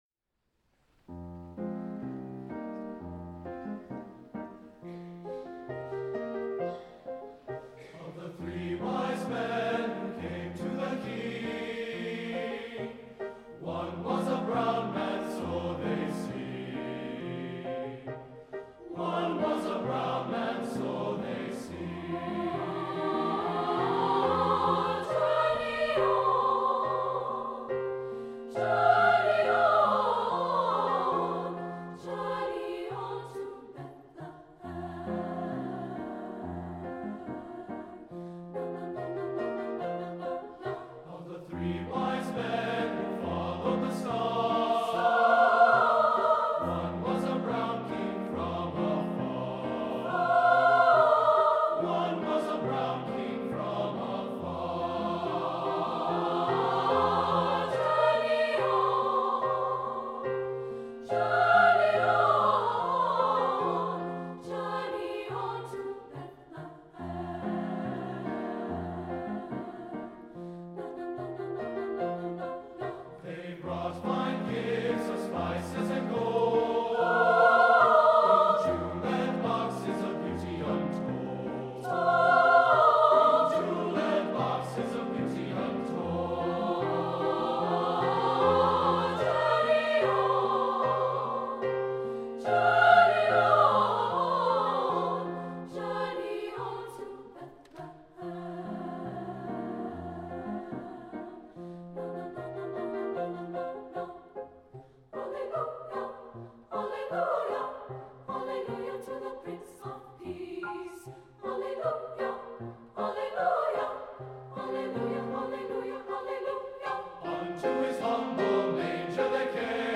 SATB and piano